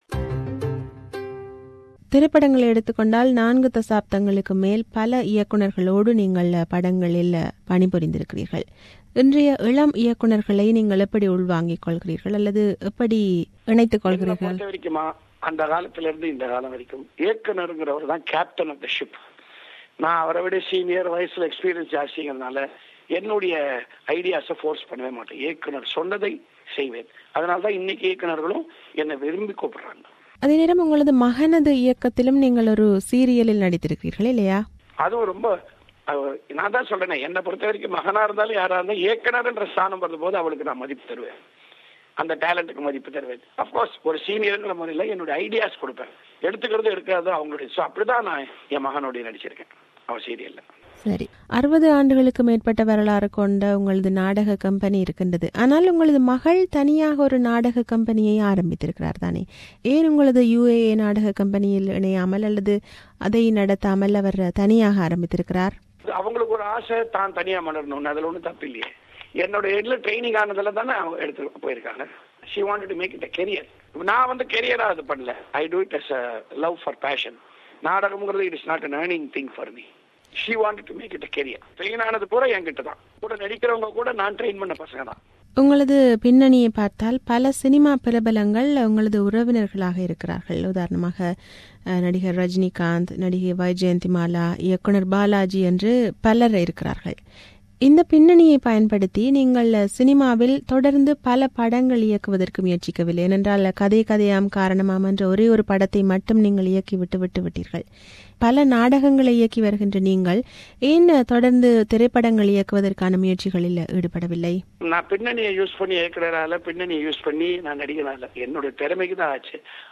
An interview with Y. Gee. Mahendra P03